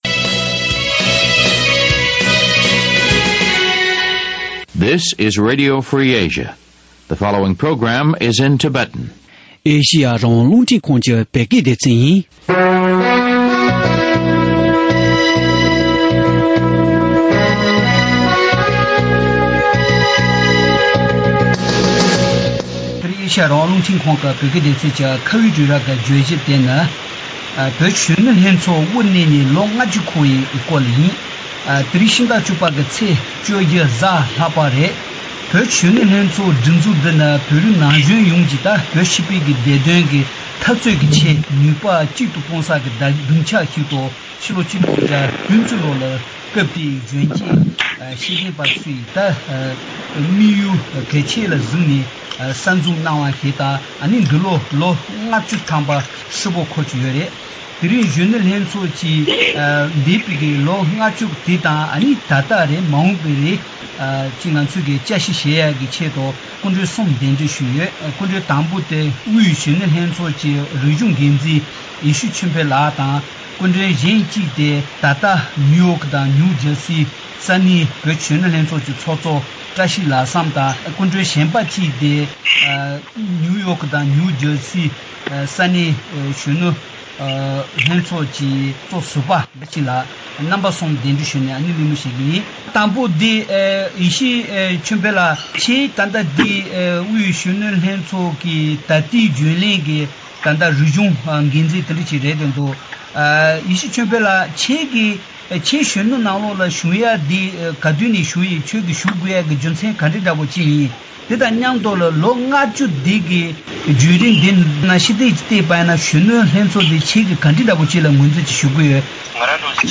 བོད་ཀྱི་གཞོན་ནུ་ལྷན་ཚོགས་གསར་བཙུགས་ཀྱིས་ལོ་ངོ་༥༠འཁོར་བ་དང་འབྲེལ་གཞོན་ནུ་ལྷན་ཚོགས་ཀྱི་འདས་དང་ད་ལྟ་མ་འོངས་པའི་སྐོར་གླེང་མོལ་ཞུས་པ།